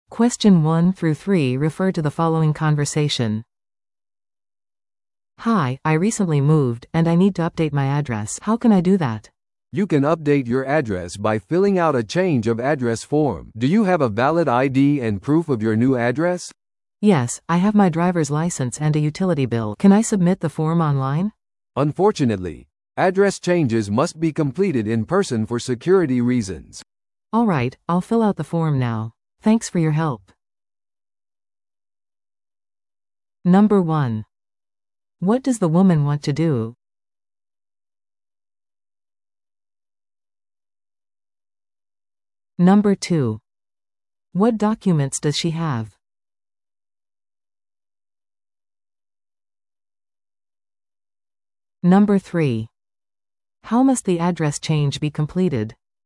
TOEICⓇ対策 Part 3｜住所変更手続きに関する銀行での対話 – 音声付き No.41